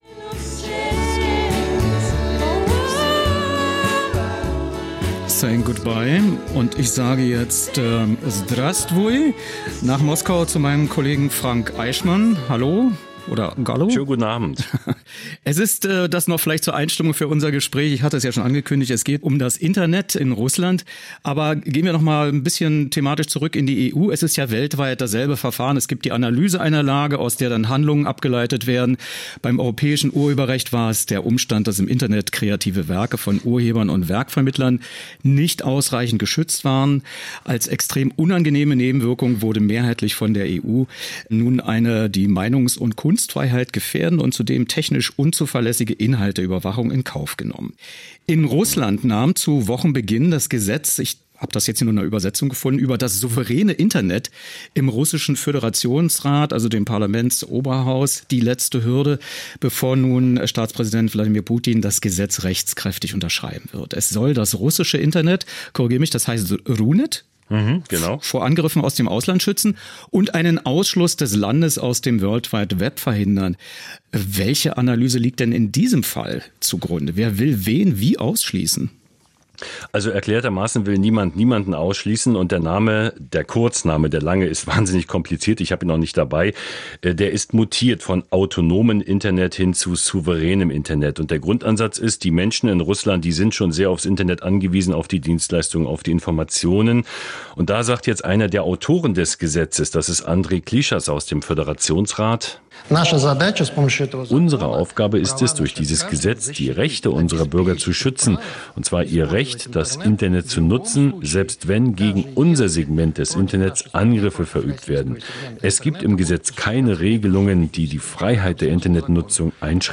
Was: Leitungsgespräch zur neuen russischen Internet-Gesetz
* Andrei Alexandrowitsch Klischas, Politiker, Partei einiges Russland, Mitglied des Föderationsrates, einer der Autoren des neuen Gesetzes
* Sergej Dimitrijewitsch Leonow, Politiker, Liberal-Demokratische Partei Russlands (LDPR), Mitglied des Föderationsrates, Smolensk
Wo: Potsdam < --> Moskau